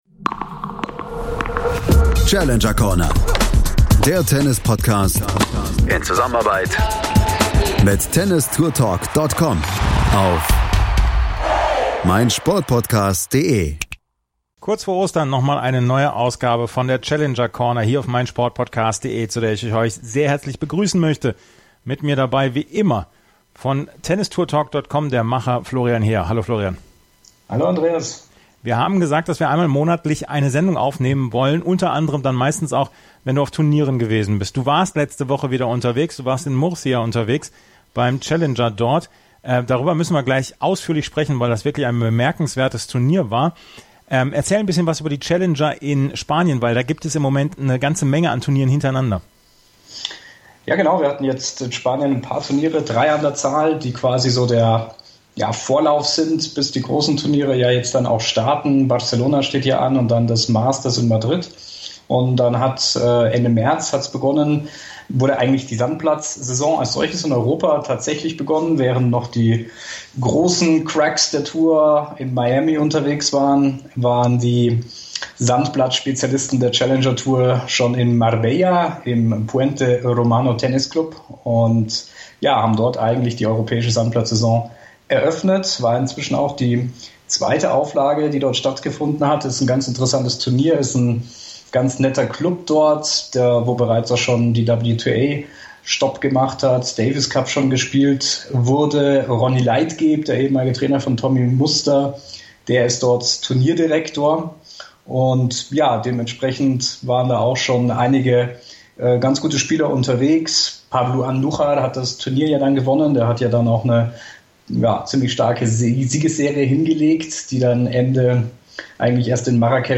Er sprach mit dem Turniersieger Ricardo Carballes Baena (auf Spanisch!), mit dem Finalisten Mikael Ymer, dem Halbfinalisten Kimmer Coppejans und dem Doppelsieger Marcus Daniell.